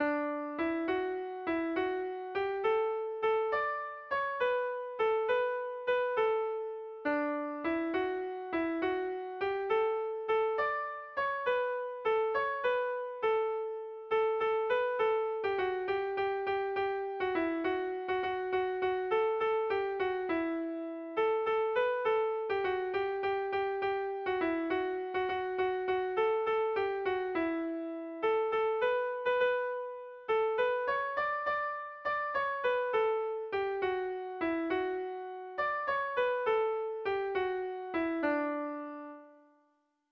Erromantzea
ABDE